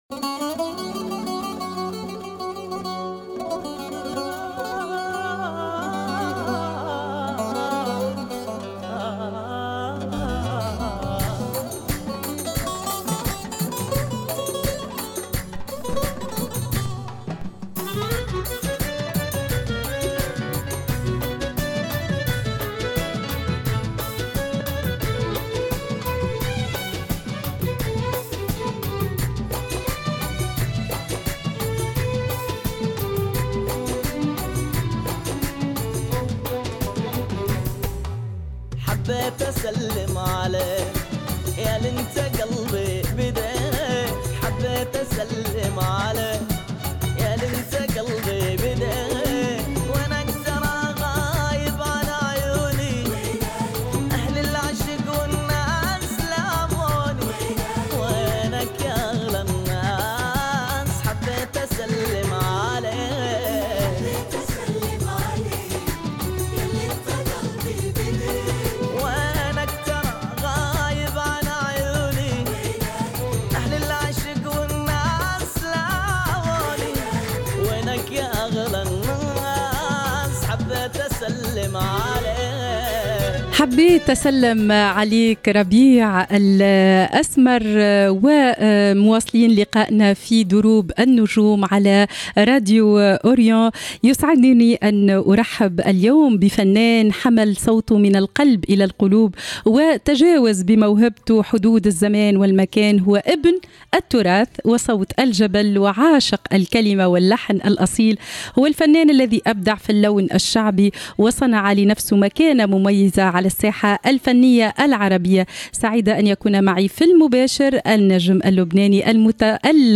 كما غنى في المباشر أغاني تبث لأول مرة حصريا على إذاعة الشرق